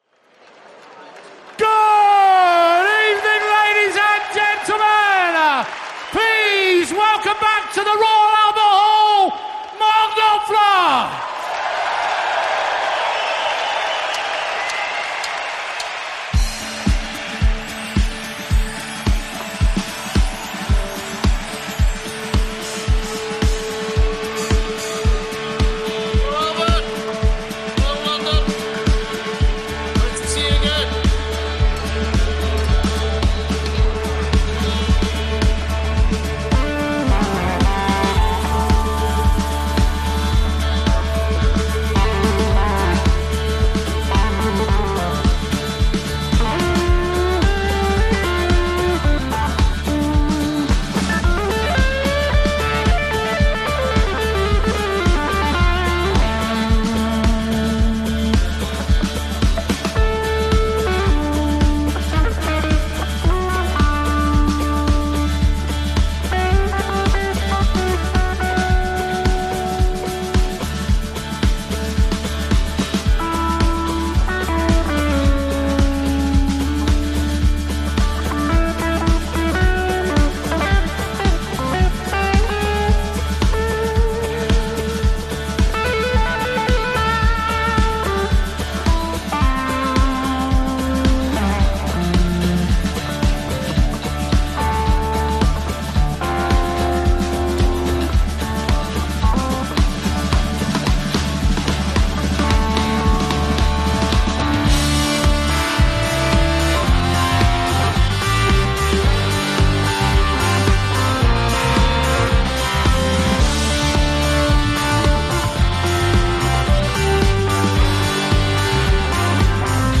format: 2 x 5" live
keyboards
guitar
piano
whistle and flute
fiddle and cittern
bass
percussion
drums
saxaphone
trumpet